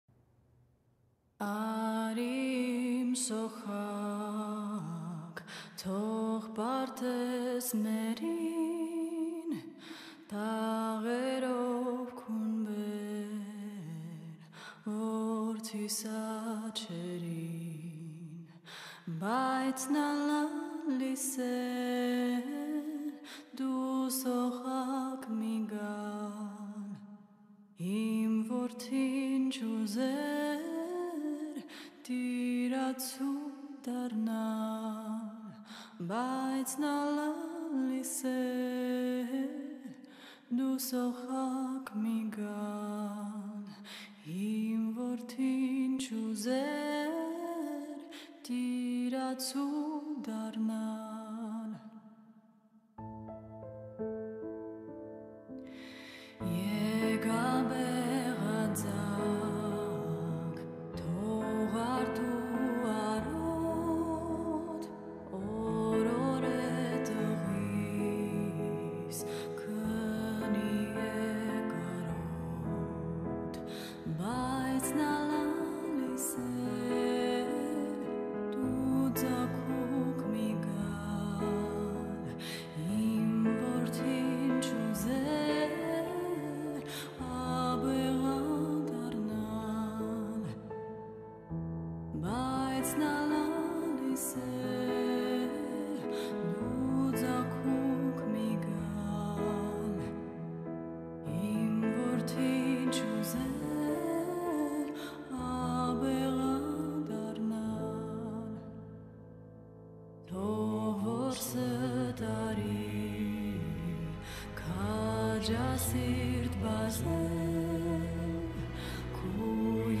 The result is a stunning, melancholic delivery.